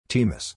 Temus (pronounced /tɛɛmʌs/
temus_pronounciation.mp3